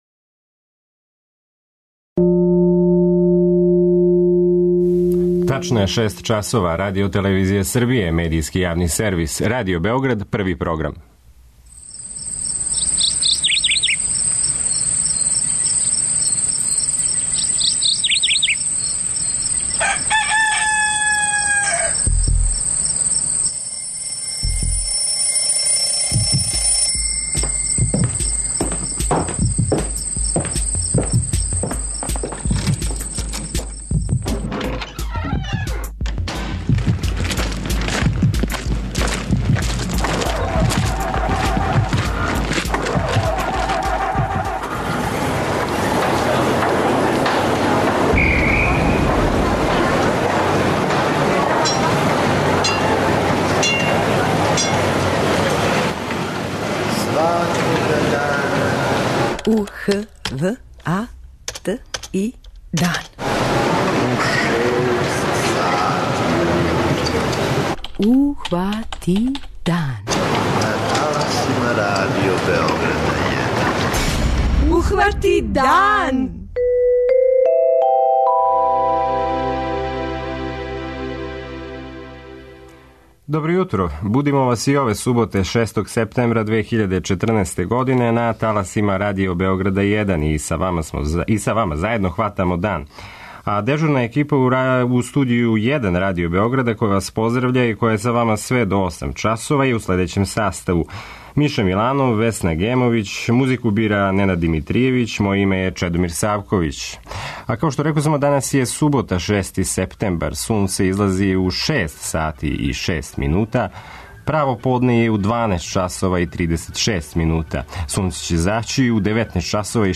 преузми : 57.31 MB Ухвати дан Autor: Група аутора Јутарњи програм Радио Београда 1!